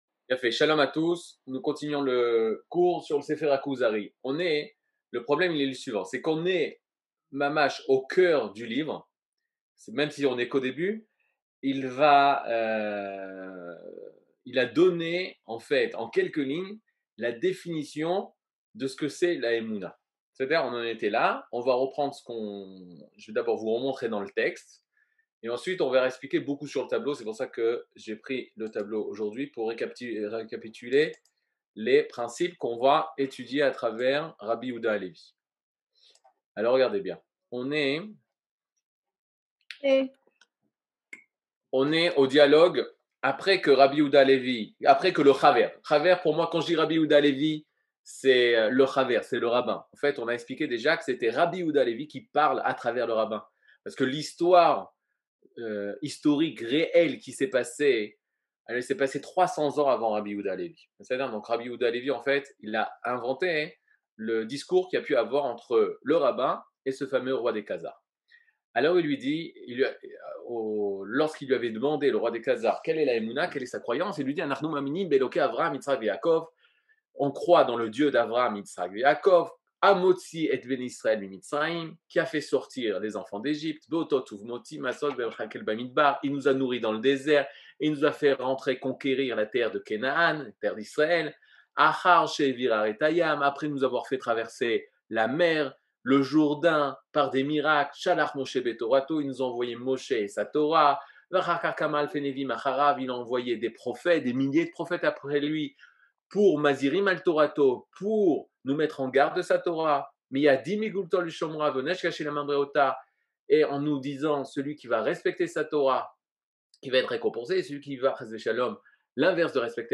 Catégorie Le livre du Kuzari partie 15 00:58:33 Le livre du Kuzari partie 15 cours du 16 mai 2022 58MIN Télécharger AUDIO MP3 (53.6 Mo) Télécharger VIDEO MP4 (151.16 Mo) TAGS : Mini-cours Voir aussi ?